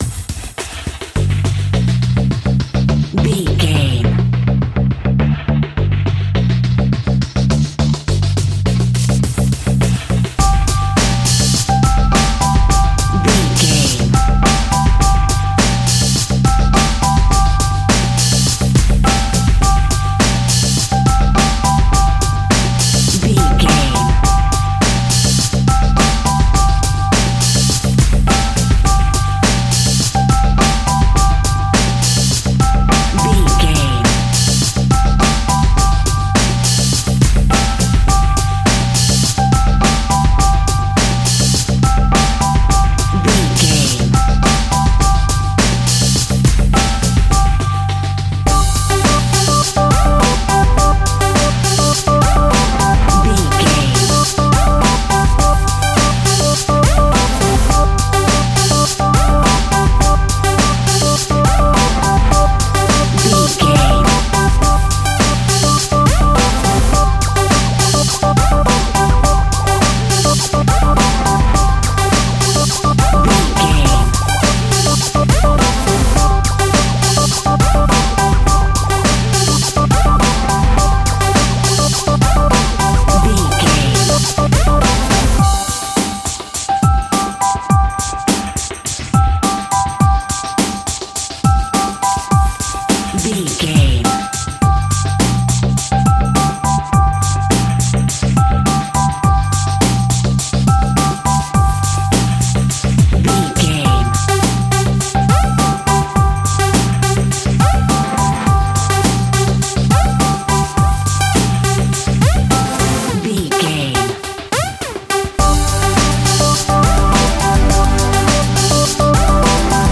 Ionian/Major
hip hop
hip hop instrumentals
downtempo
synth lead
synth bass
synth drums
hip hop loops